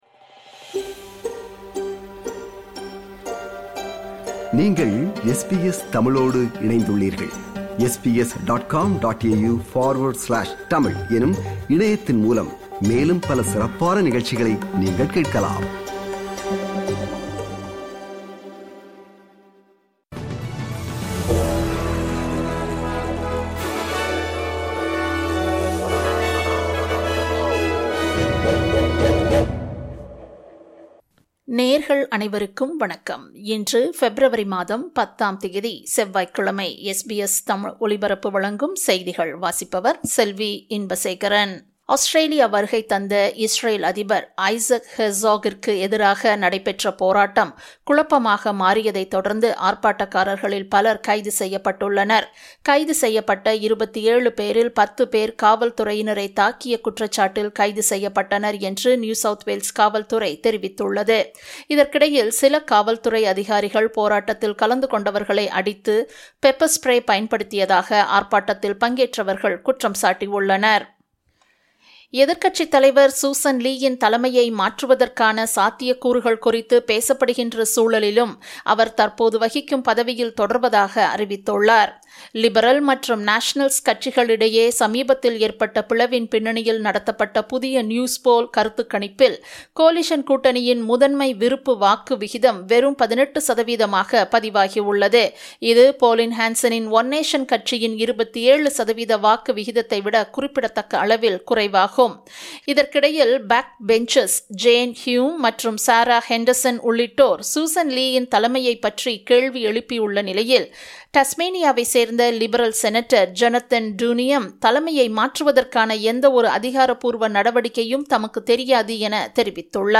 SBS தமிழ் ஒலிபரப்பின் இன்றைய (செவ்வாய்க்கிழமை 10/02/2026) செய்திகள்.